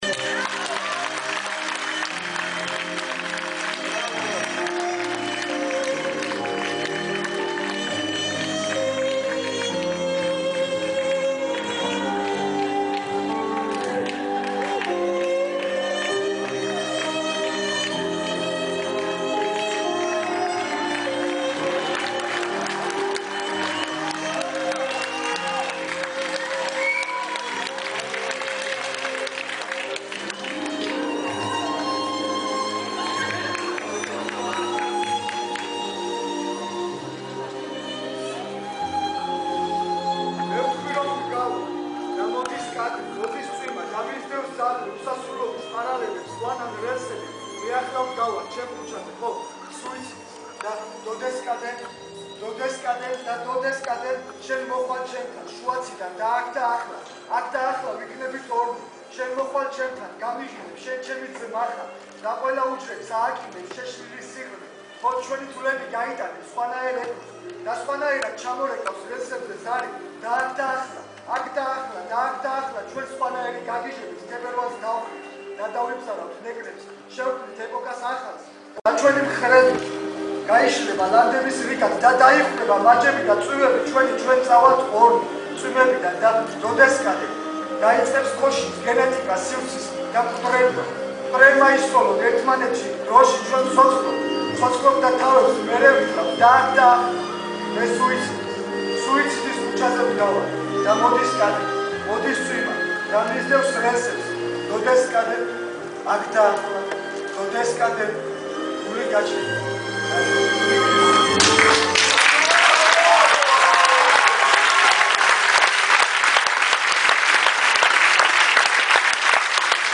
ჟანრი: პოეზია